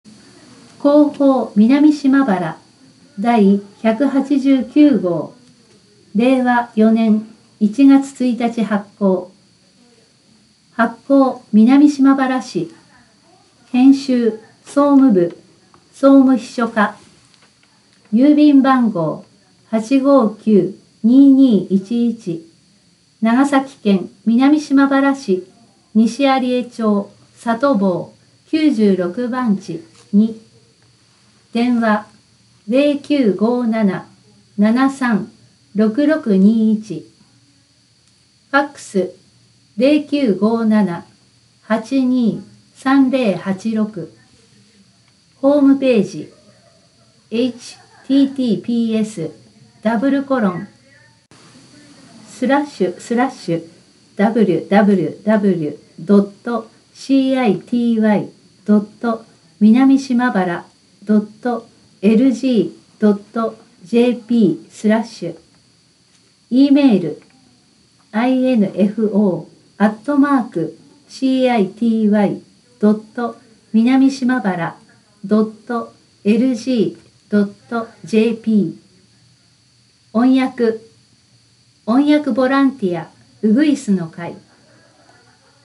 音訳（※声の広報紙）
この音訳データは、音訳ボランティア「うぐいすの会」の皆さんにより作成された音読データをmp3形式で提供しています。